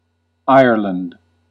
Ääntäminen
US : IPA : [ˈaɪɚ.lənd] UK : IPA : /ˈaɪə(ɹ)lənd/